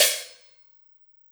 Cymbol Shard 07.wav